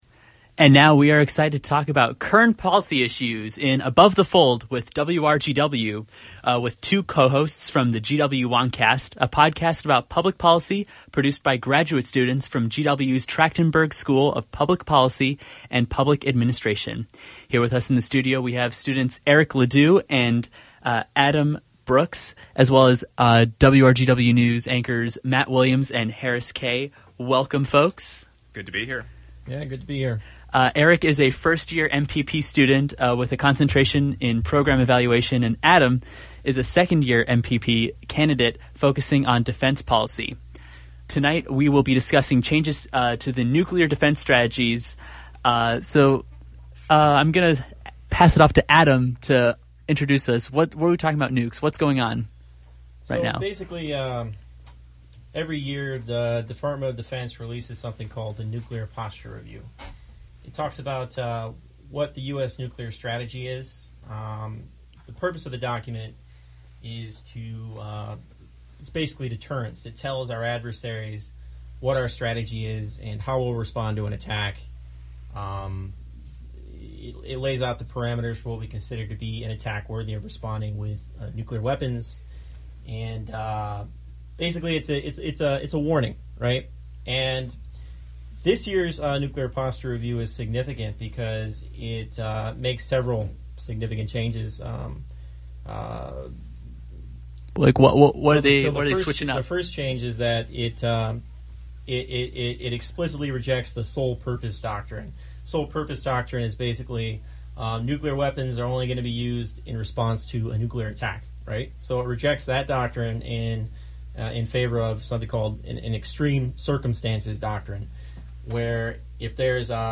Learn about the Department of Defense’s Nuclear Posture Review (NPR), which outlines the U.S. nuclear defense strategy. Hear what’s in the review and what has changed, followed by a discussion on the role nuclear weapons play today.
Join the GW WonkCast team live on air with WRGW News to hear where policy and breaking news intersect.